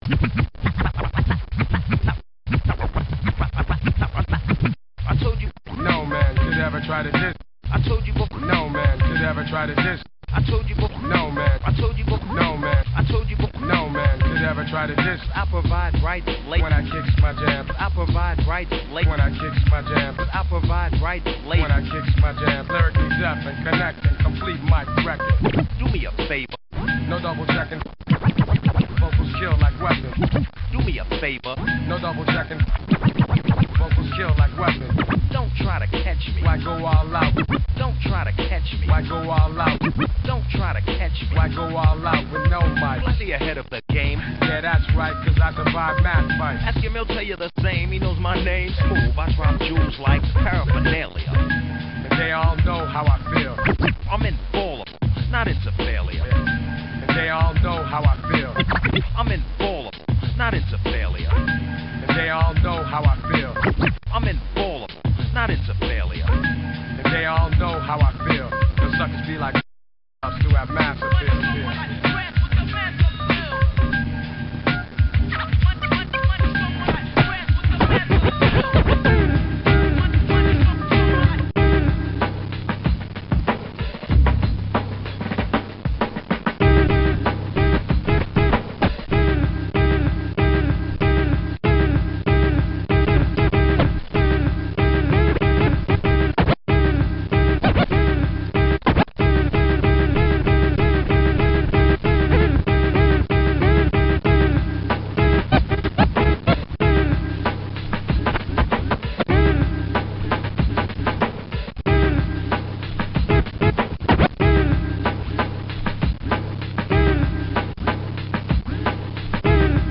two turntables: